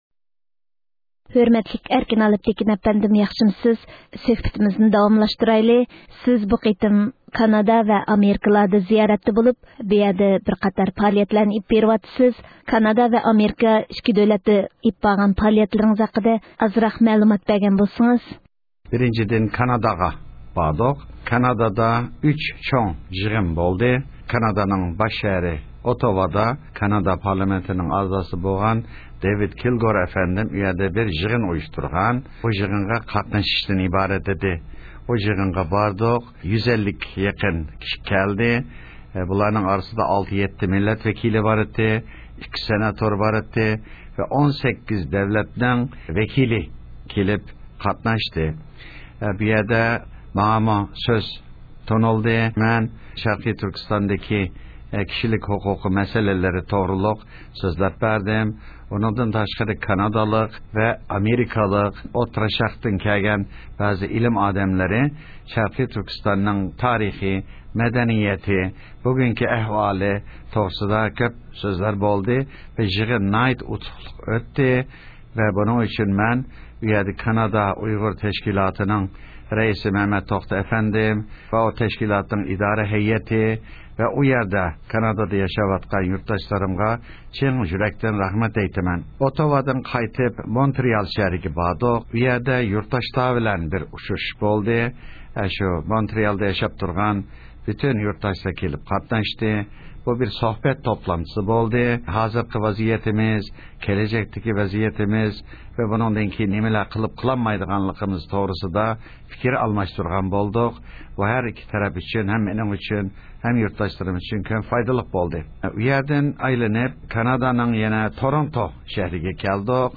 بۇ شۇ قېتىم، ئەركىن ئالىپتېكىن ئەپەندىنىڭ كانادا ۋە ئامېرىكىدادىكى زىيارىتى جەريانىدىكى پائالىيەتلىرى ھەققىدىكى سۆھبىتىمىزنى دىققىتىڭلارغا سۇنىمىز.